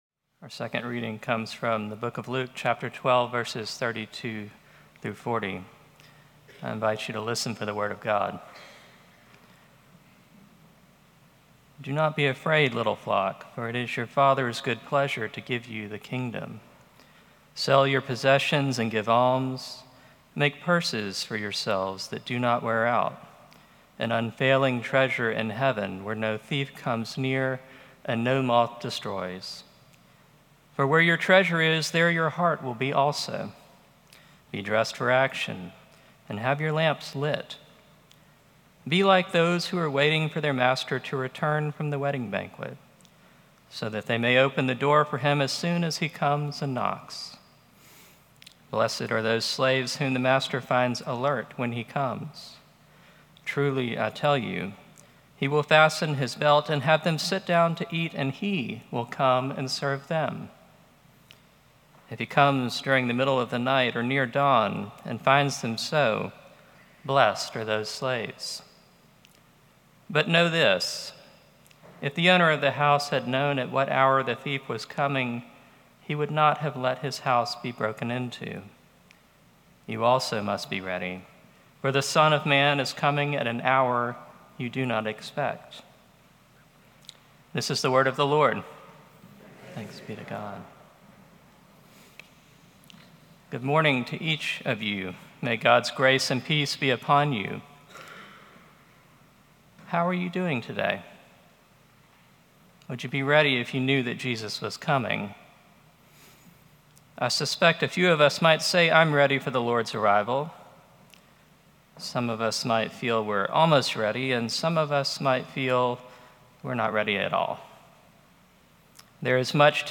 Sermon+8-10-25.mp3